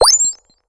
gain_xp_03.ogg